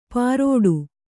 ♪ pārōḍu